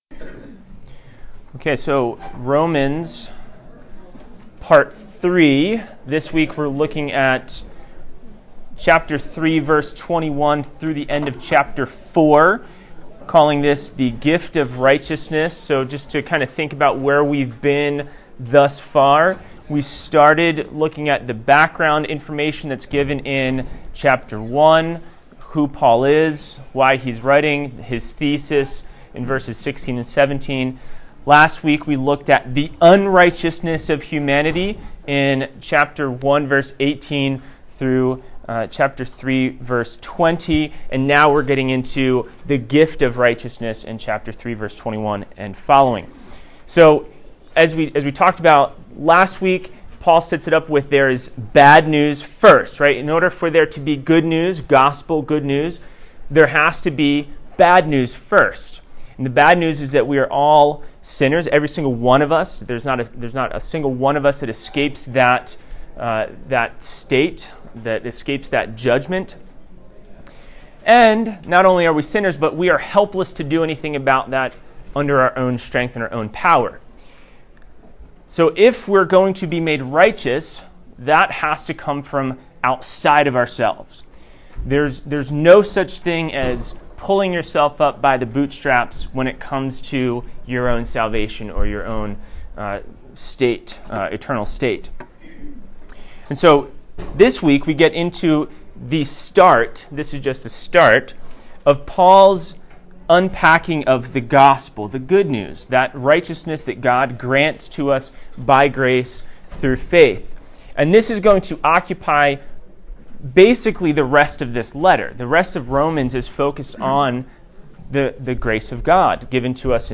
Sunday School Classes